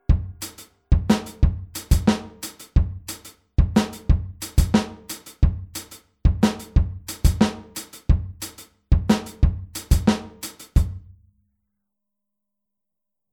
Nach dem 4tel-Offbeat setzen wir ein Echo hinzu
Hier spielen wir den Offbeat mit der rechten Hand wieder auf dem HiHat.